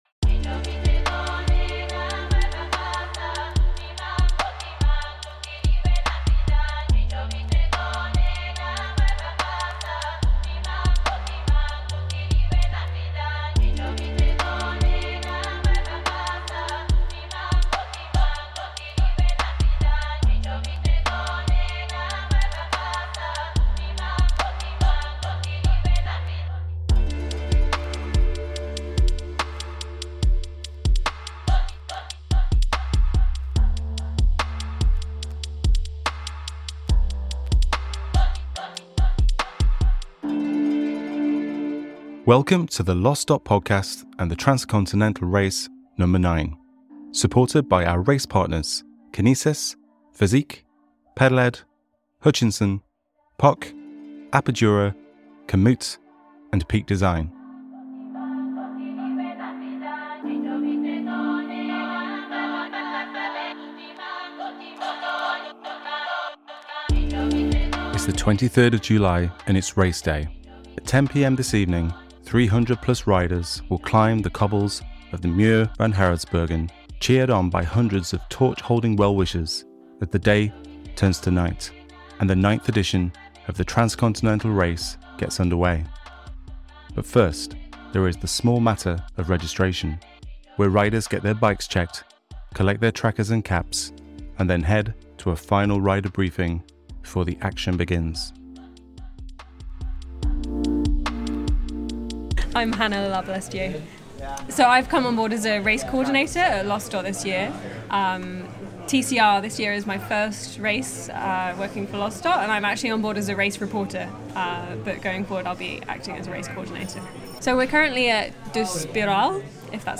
Enjoy the Race day buzz!